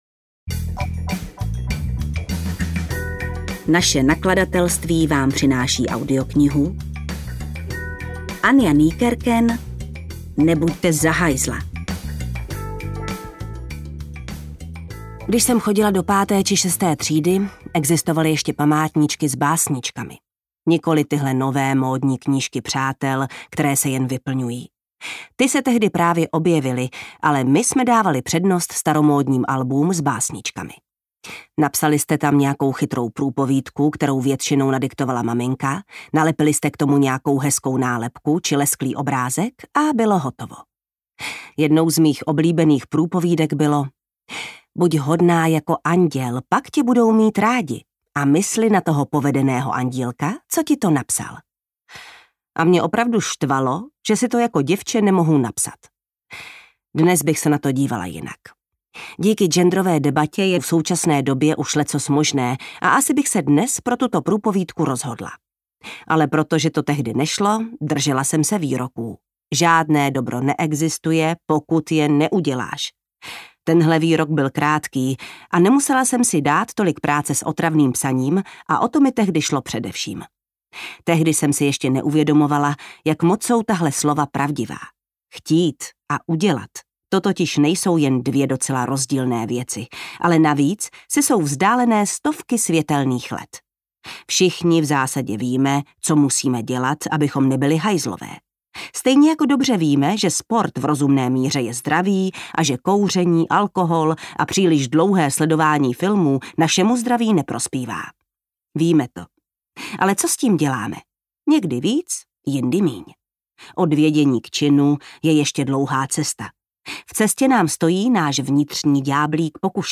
Nebuďte za hajzla audiokniha
Ukázka z knihy
• InterpretJana Stryková